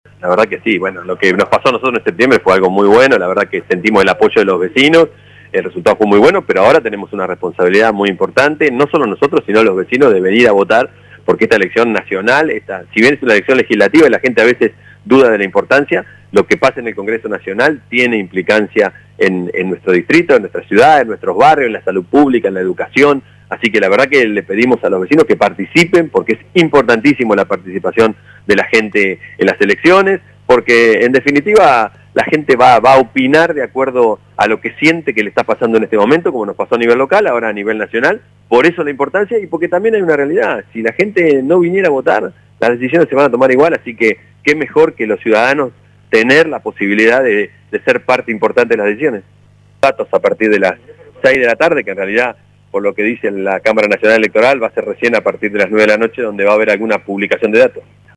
El intendente Pablo Garate sufragó poco después de las 9:00 y luego, en contacto con el móvil de LU 24 sostuvo “Hoy tenemos una responsabilidad muy importante, invito a la gente a que participe porque es importantísima, ya que lo que pasa en el Congreso se refleja en los municipios”, dijo.